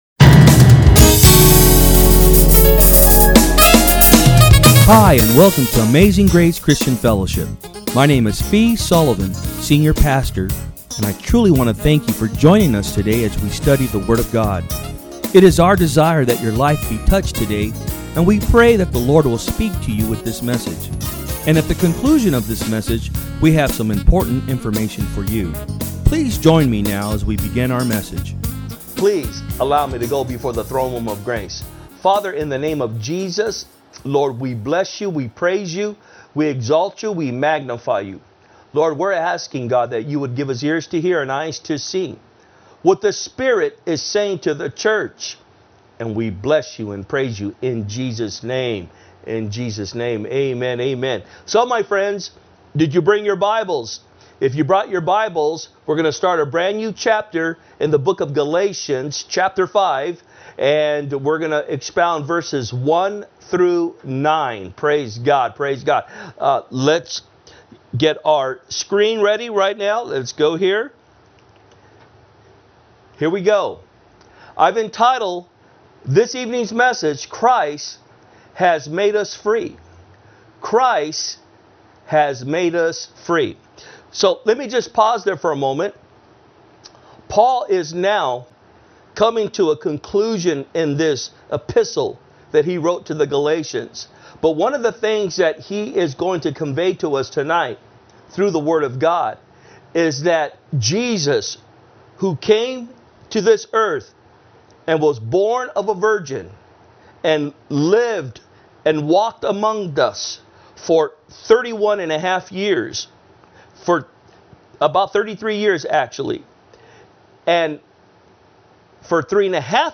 From Service: "Wednesday Pm"